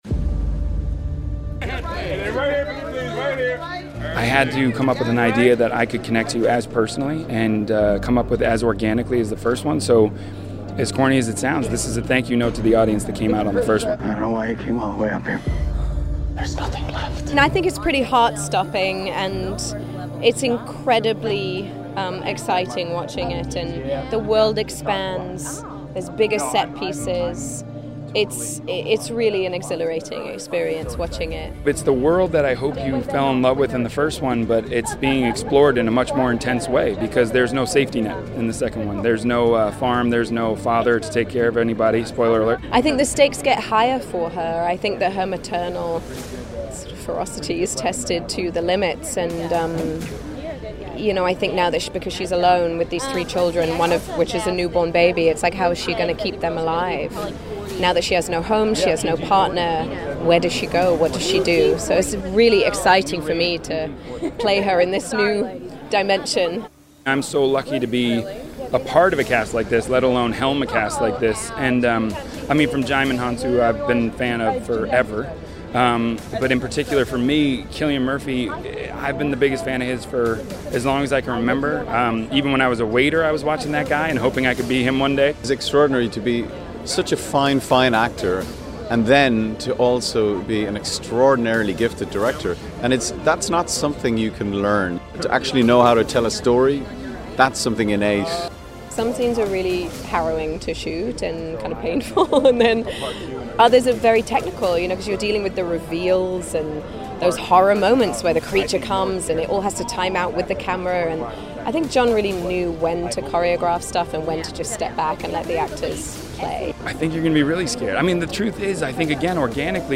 John Krasinski and Emily Blunt Stun at A Quiet Place Part II NYC Premiere
A Quiet Place Part II, writer/director/producer, John Krasinski called the sequel “a thank you note” to the people who came out to see the first film at the New York City premiere.